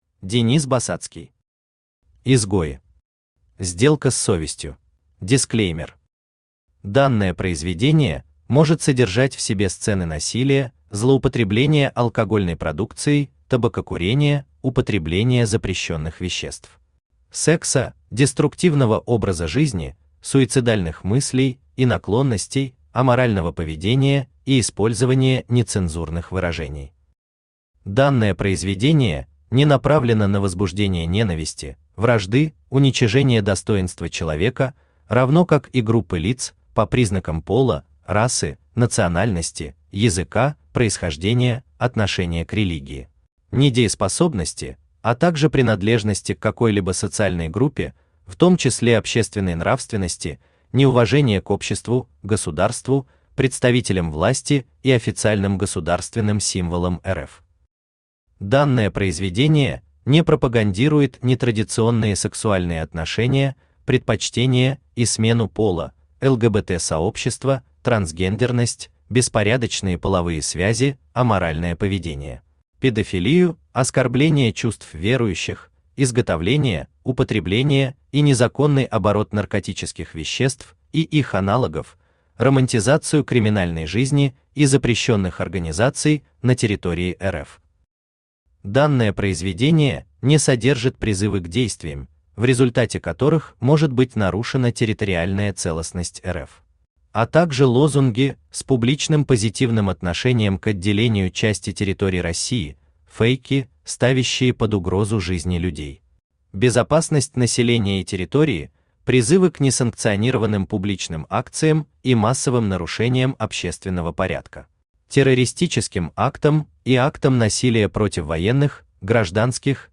Аудиокнига Изгои. Сделка с совестью | Библиотека аудиокниг
Сделка с совестью Автор Денис Басацкий Читает аудиокнигу Авточтец ЛитРес.